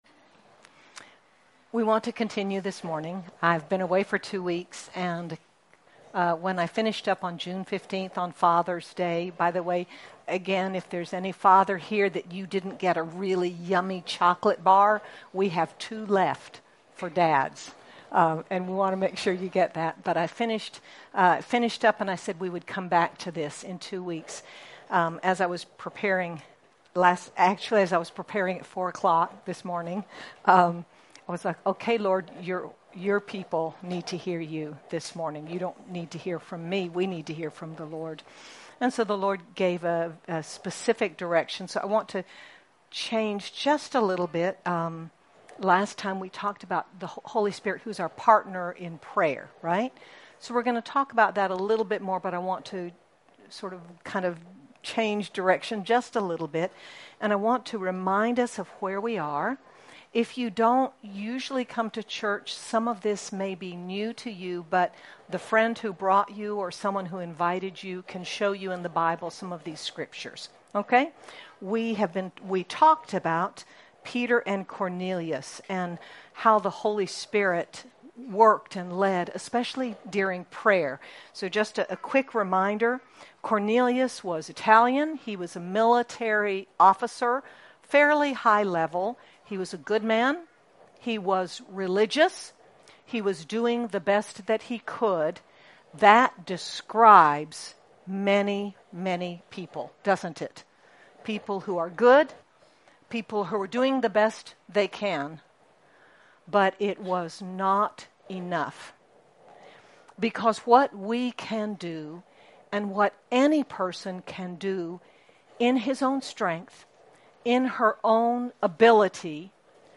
Be encouraged as we look at Christians who partnered with the Holy Spirit for God’s purposes. Sermon by